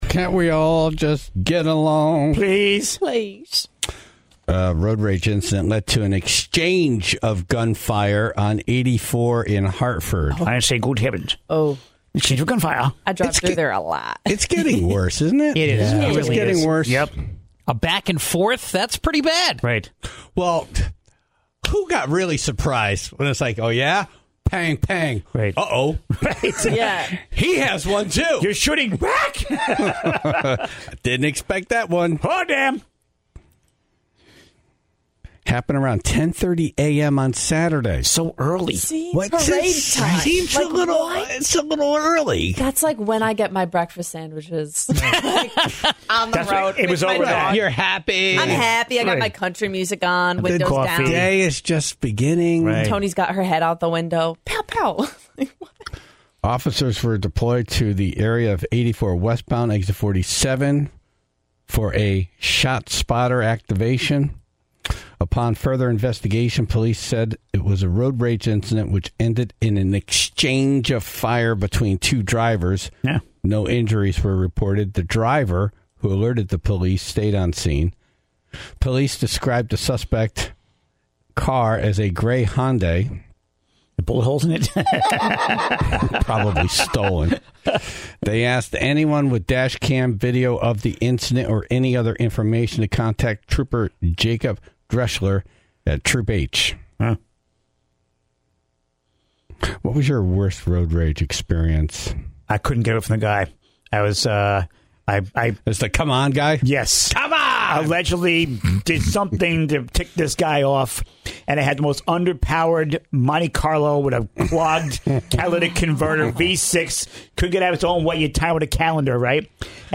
The Tribe called in to ask for help with their dog issues.